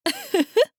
大人女性│女魔導師│リアクションボイス│商用利用可 フリーボイス素材 - freevoice4creators
笑う